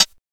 • Clean High-Hat D Key 66.wav
Royality free closed high hat sound tuned to the D note. Loudest frequency: 4775Hz
clean-high-hat-d-key-66-Mpc.wav